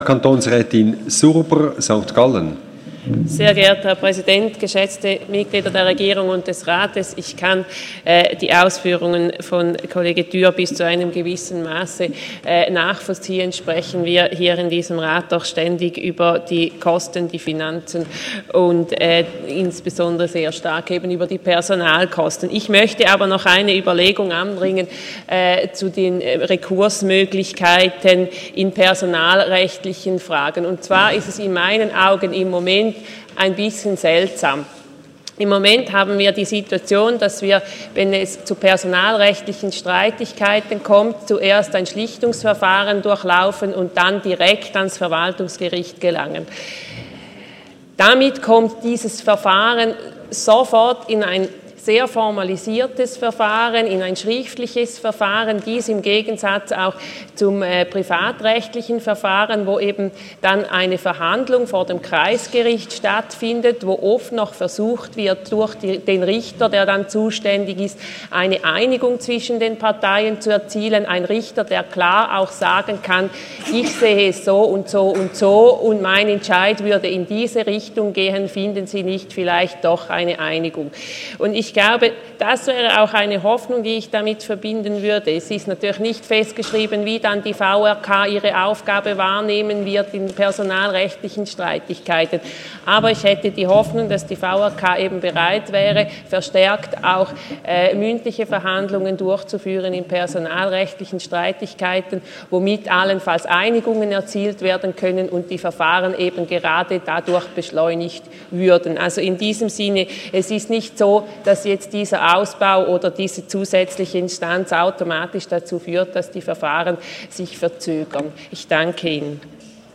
20.9.2016Wortmeldung
Session des Kantonsrates vom 19. und 20. September 2016